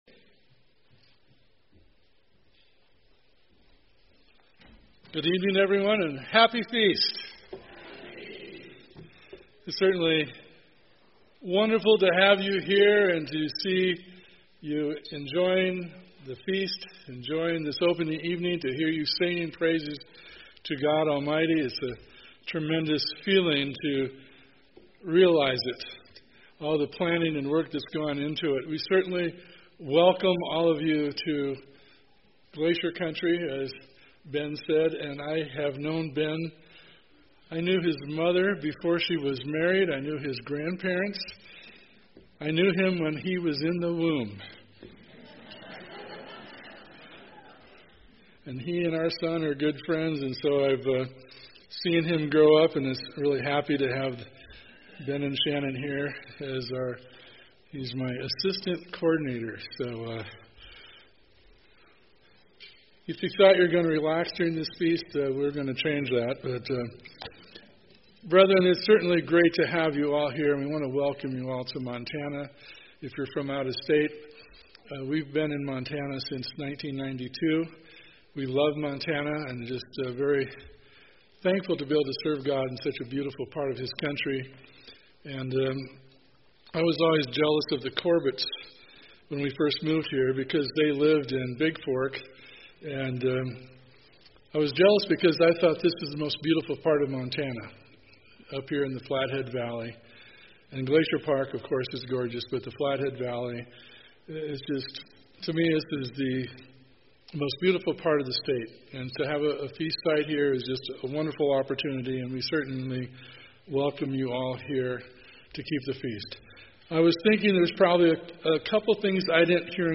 Feast of Tabernacles opening night welcome message.
This sermon was given at the Glacier Country, Montana 2018 Feast site.